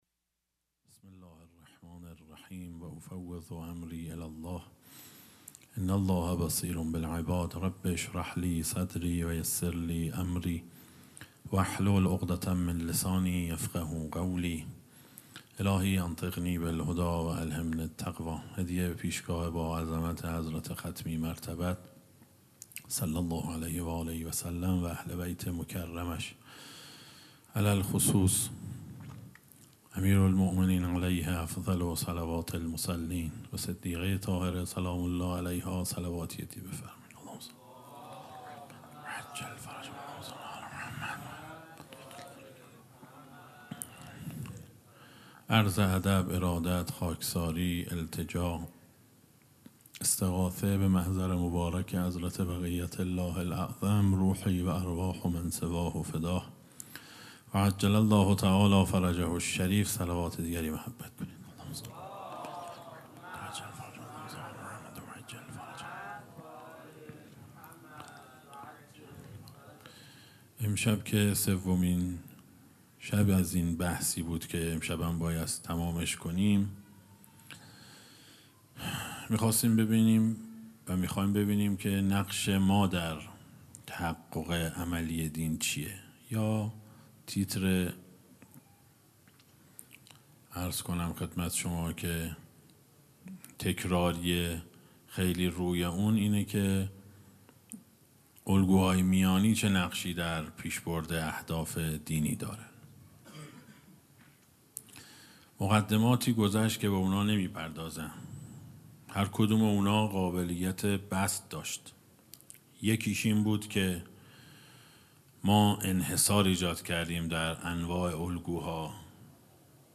جلسه هفتگی | شهادت امام هادی علیه‌السلام ۱۴۰۲